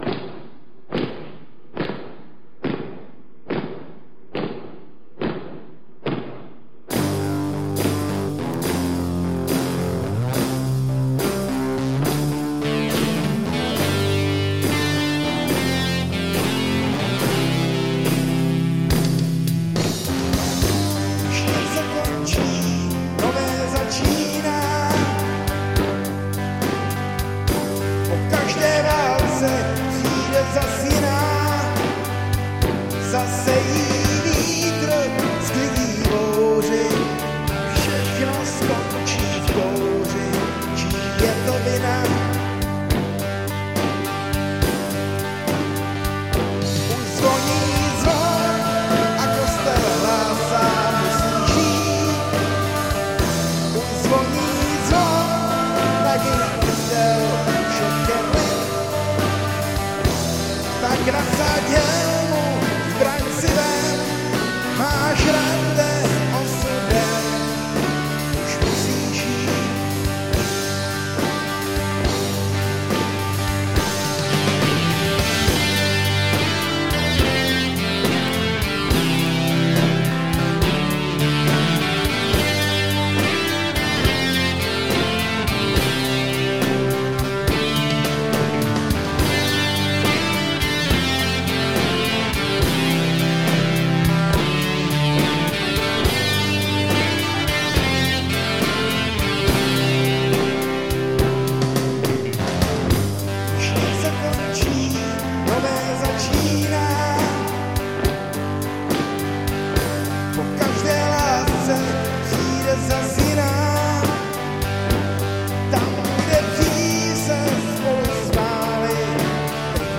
Ukázky filmové hudební tvorby
hudební provedení a zpěv